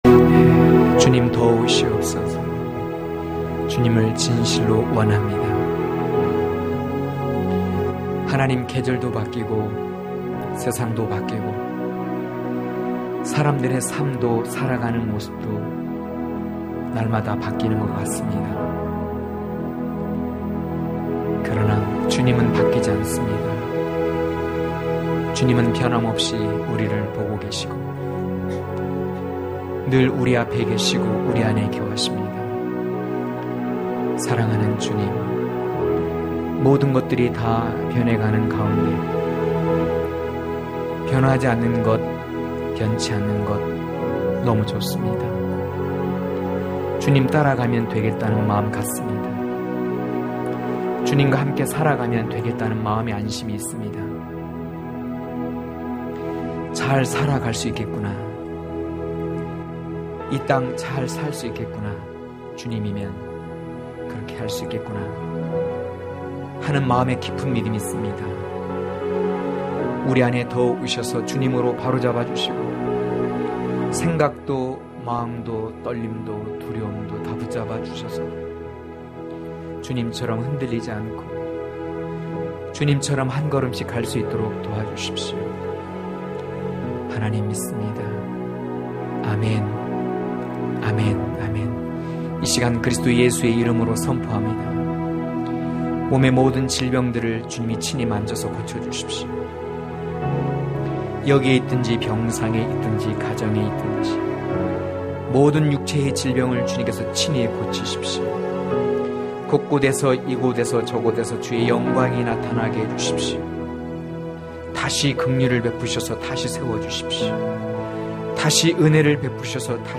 강해설교 - 06.노루와 사슴 같은 신부(아2장5-7절)